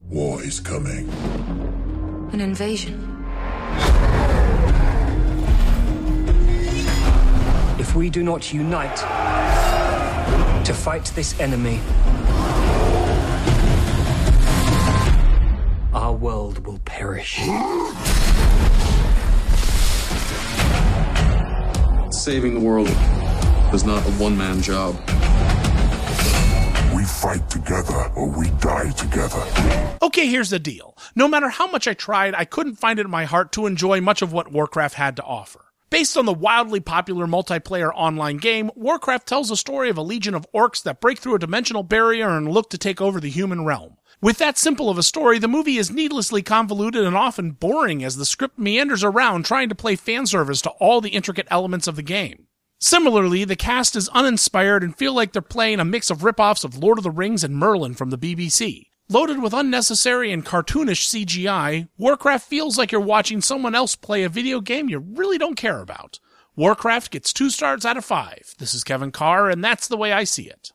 ‘Warcraft’ Radio Review